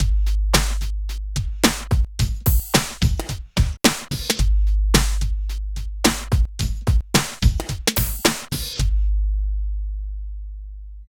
103 LOOP  -L.wav